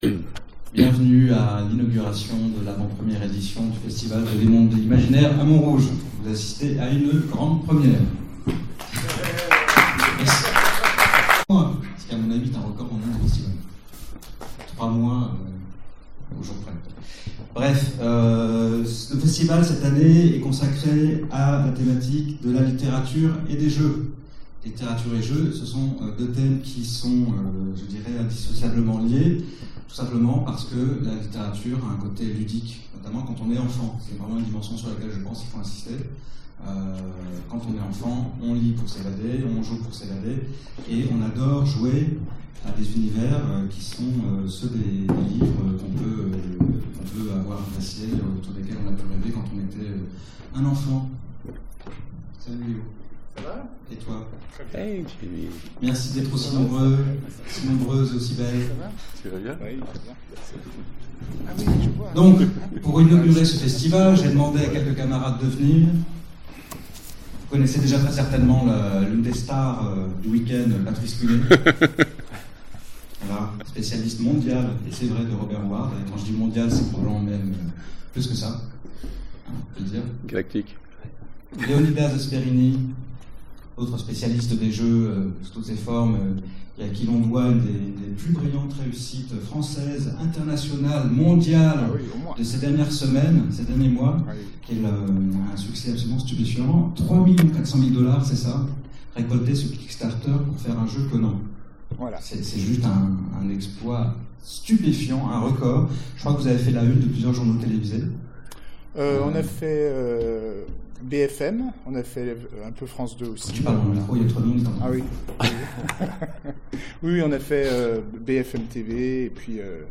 Conférence FMI 2015 JDR et littérature